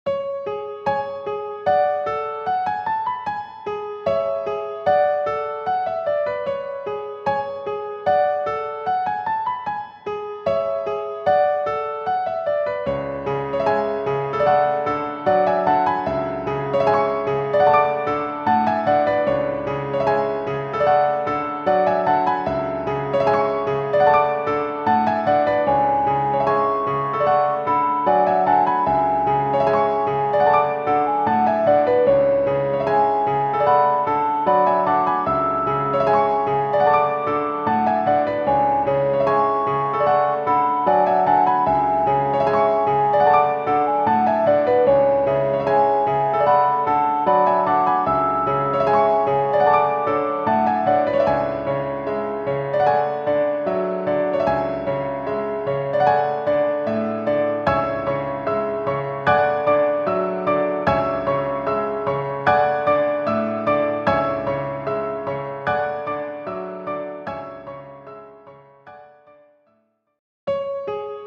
-oggをループ化-   暗い 重い 1:14 mp3